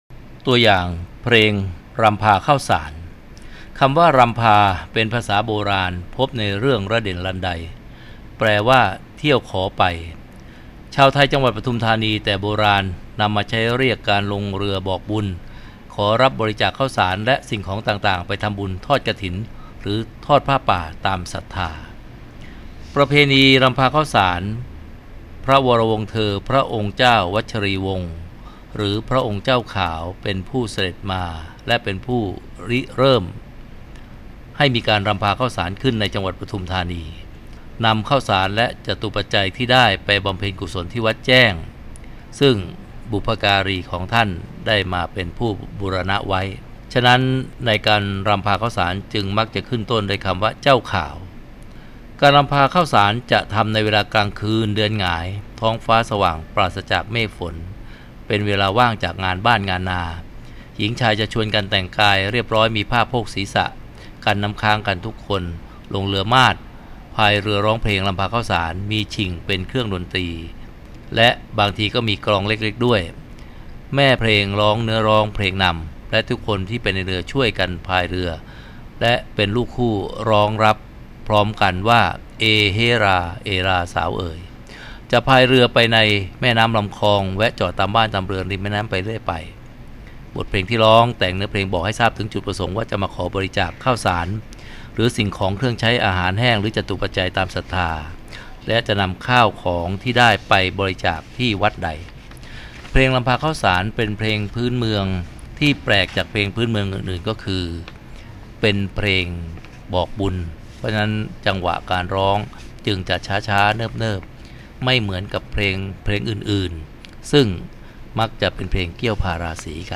เสียงบรรยาย เพลงรำพาข้าวสาร